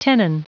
Prononciation du mot tenon en anglais (fichier audio)
Prononciation du mot : tenon